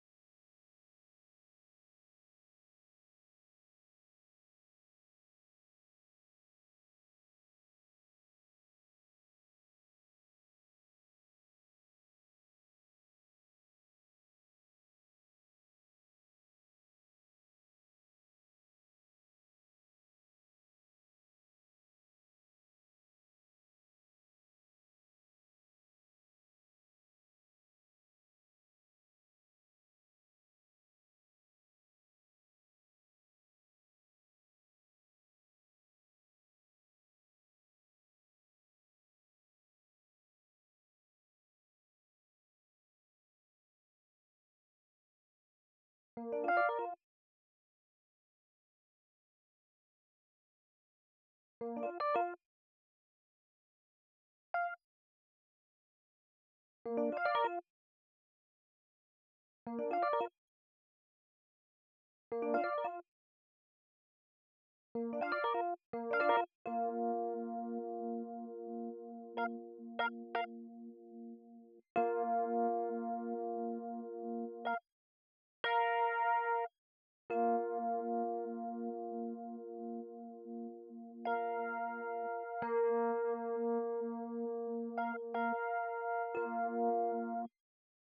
90 BPM
epiano Wide Suitcase 68385_Wide Suitcase.wav